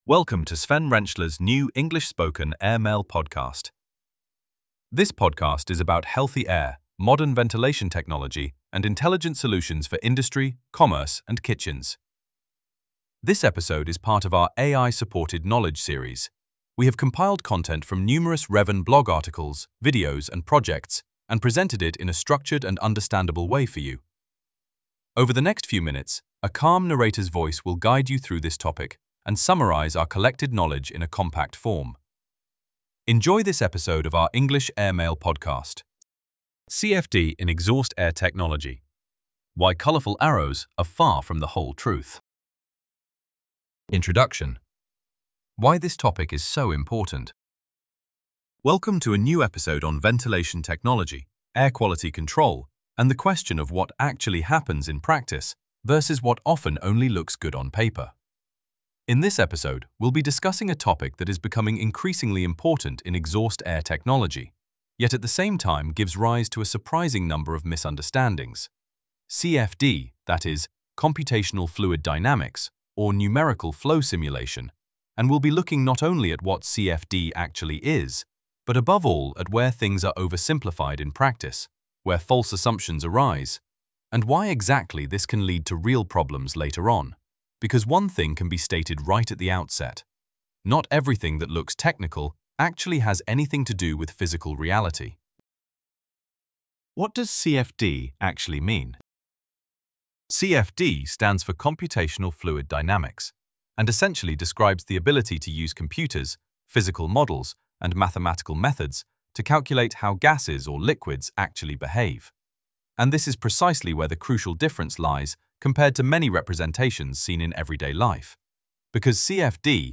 A new blog article and AI podcast explain.